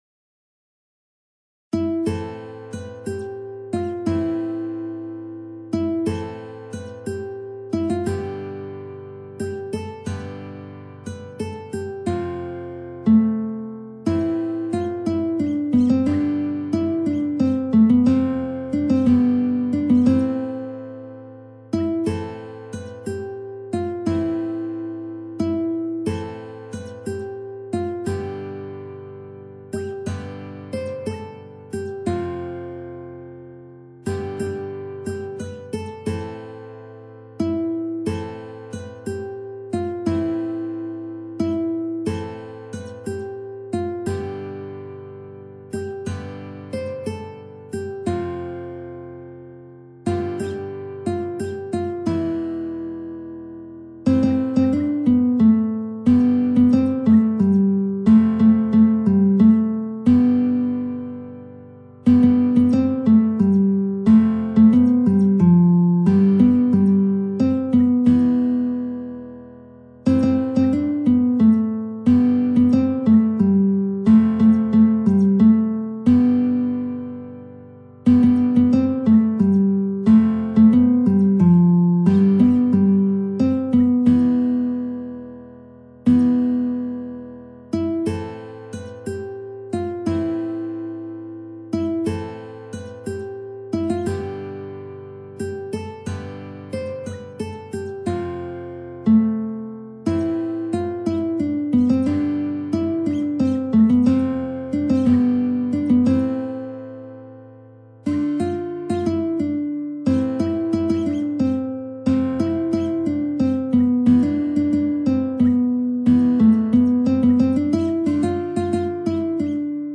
نت ملودی به همراه تبلچرو آکورد